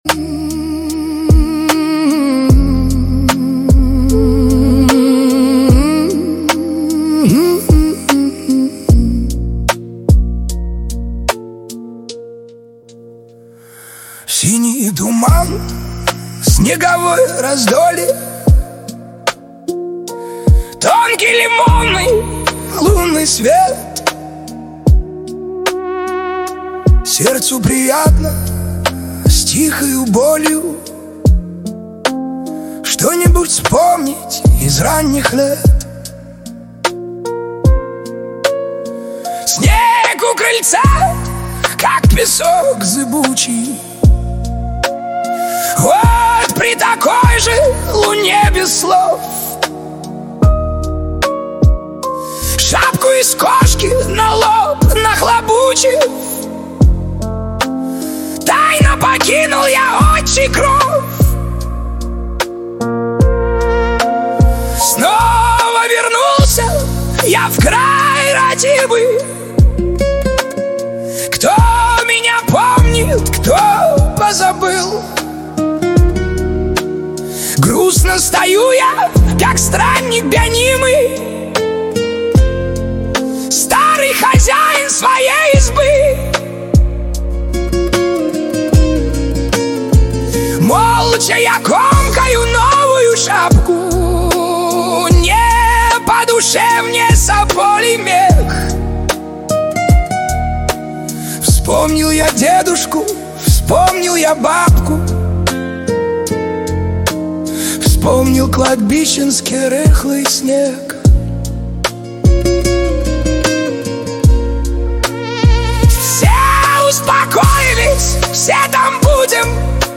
Качество: 320 kbps, stereo
Песни Суно ИИ, Нейросеть Песни 2025